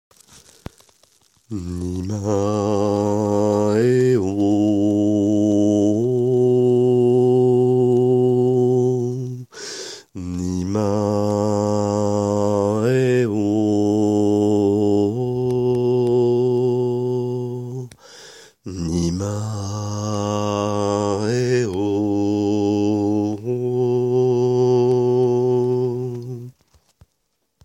Mantra d’activation et d’intégration (peut agir en complément du chargement via l’image)
mantra-janvier-2017.mp3